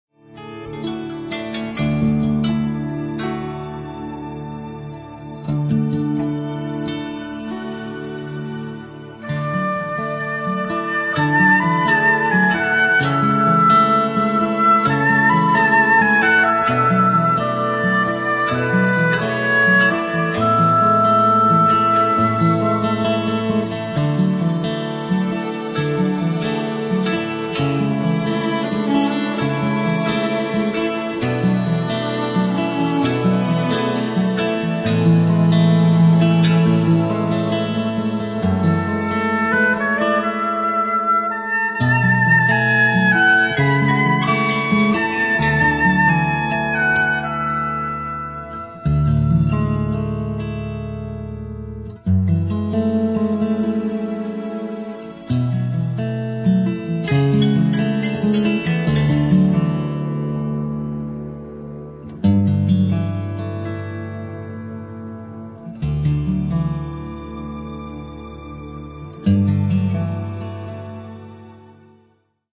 auf den Ton der Venus gestimmt.
Naturgeräusche